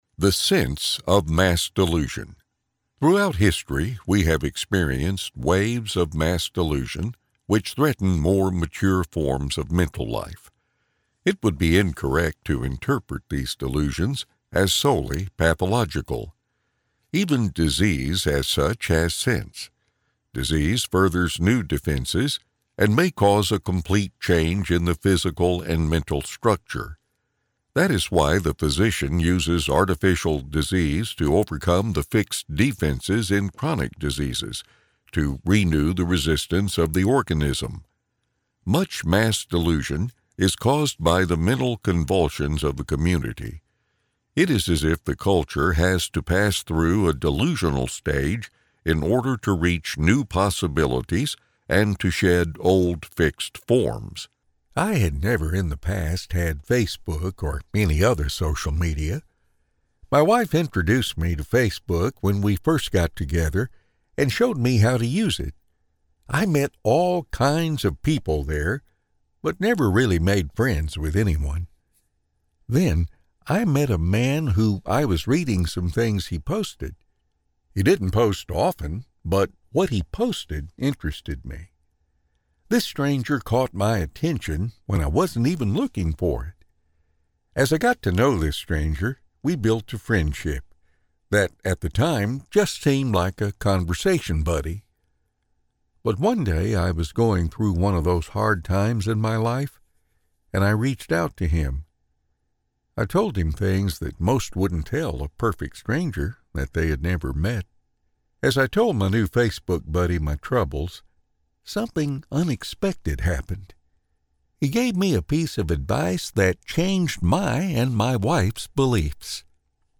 Male
Adult (30-50), Older Sound (50+)
Audiobooks
All our voice actors have professional broadcast quality recording studios.
0925AUDIOBOOK.mp3